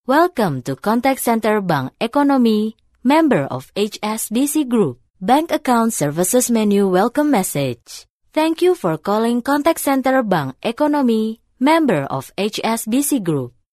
Indonesian, Female, Home Studio, 20s-40s